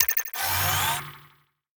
Sfx_tool_spypenguin_enter_01.ogg